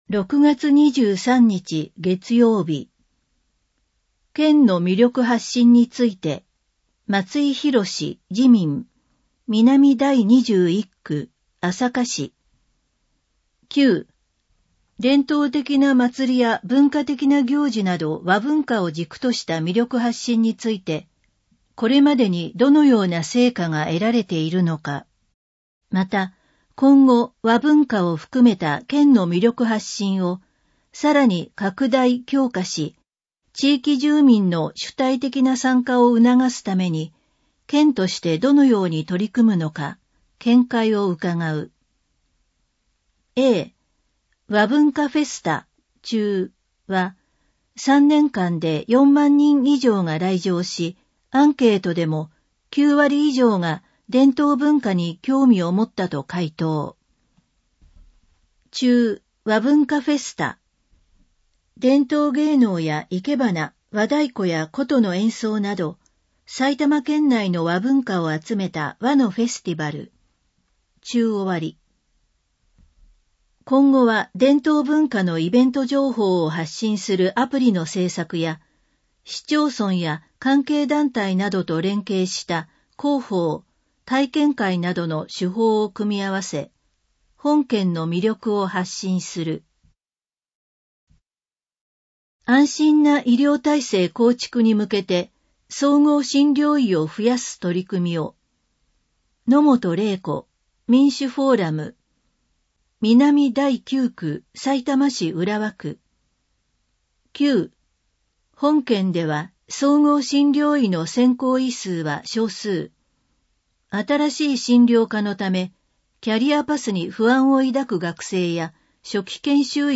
「Windows Media Player」が立ち上がり、埼玉県議会だより 182号の内容を音声（デイジー版）でご案内します。